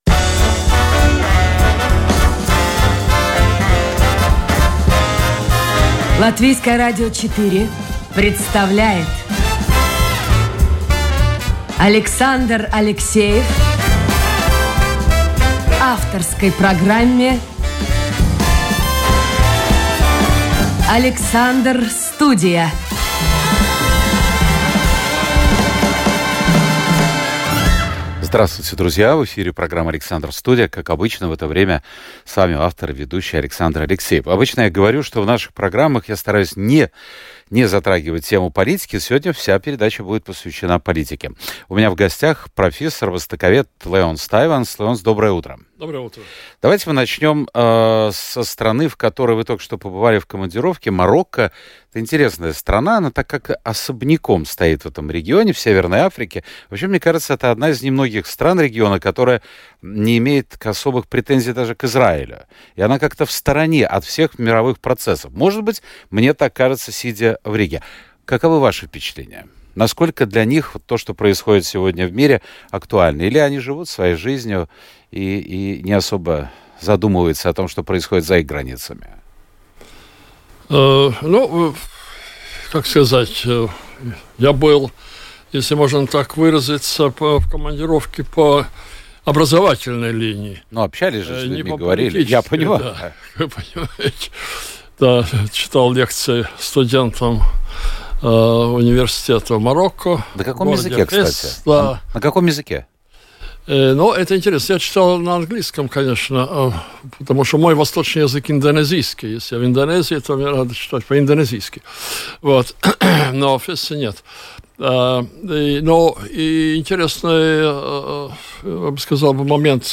Живой и непринужденный диалог со слушателями, неформальный разговор с известными людьми, ТОК-ШОУ с участием приглашенных экспертов о самых невероятных явлениях нашей жизни.